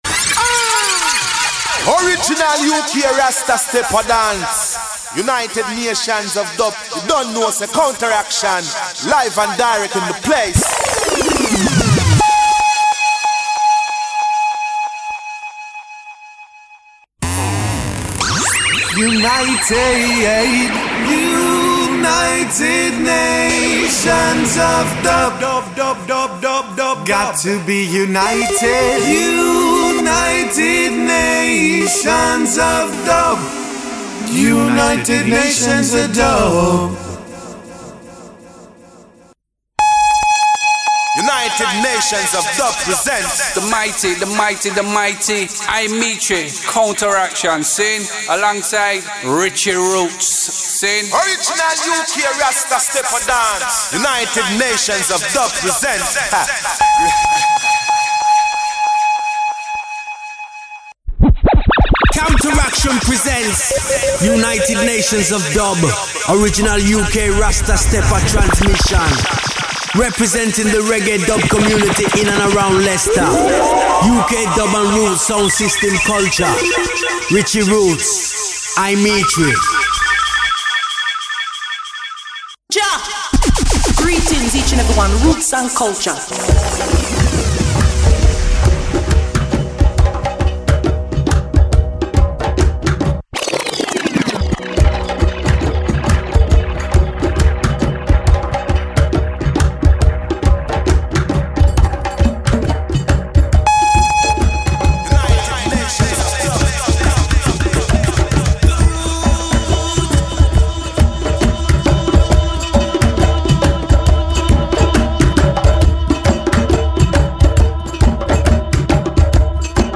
Radio DUBcast